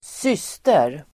Uttal: [²s'ys:ter]